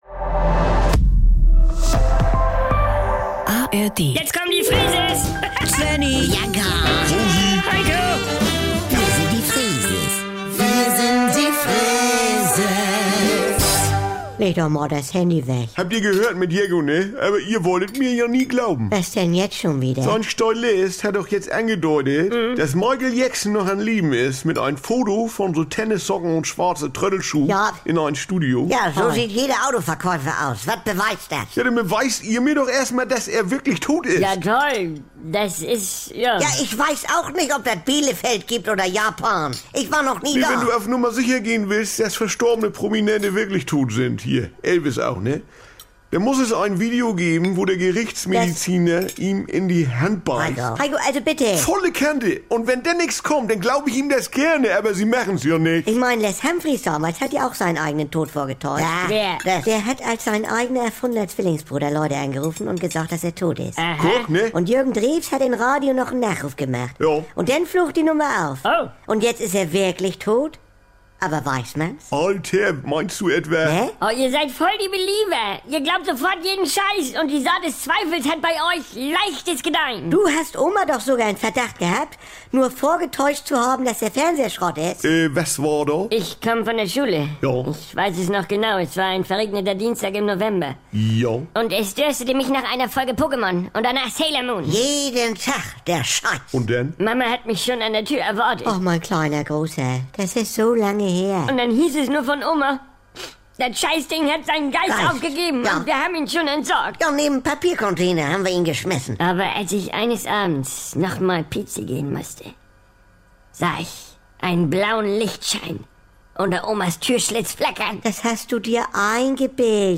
Saubere Komödien Unterhaltung NDR 2 Komödie NDR Freeses Comedy